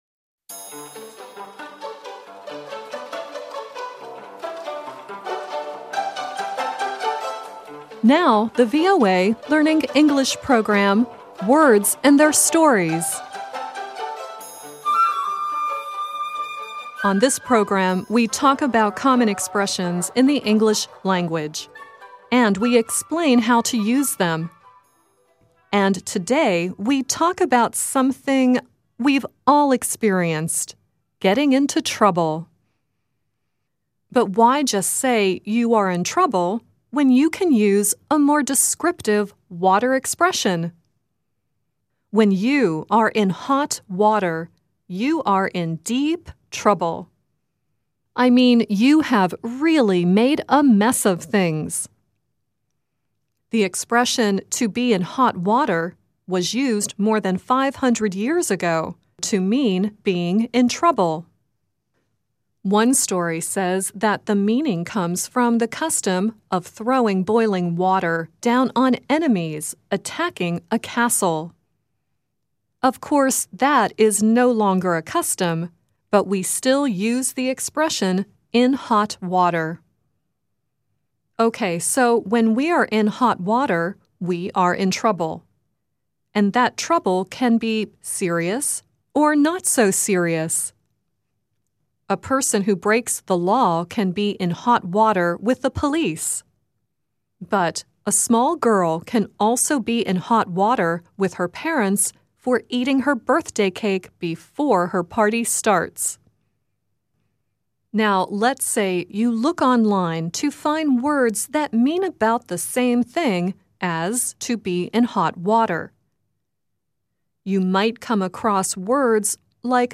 The song you heard earlier in the program is Simon and Garfunkel singing “Bridge Over Trouble Water” and at the end Vera Lynn sings “Be Like a Kettle and Sing.”